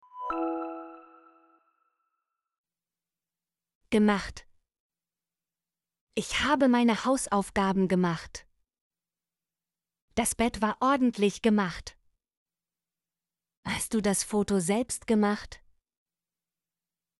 gemacht - Example Sentences & Pronunciation, German Frequency List